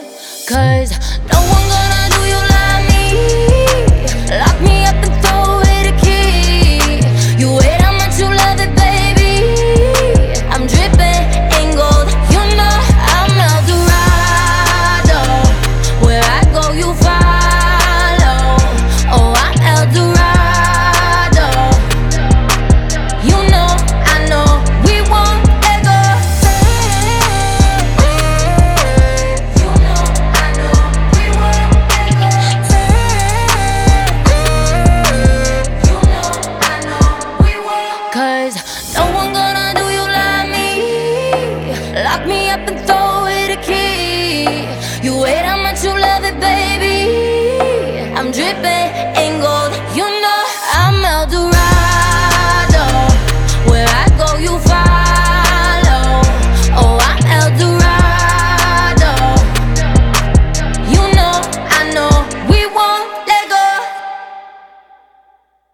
• Качество: 320, Stereo
поп
женский вокал
спокойные
красивый женский голос
Красивая мелодичная песня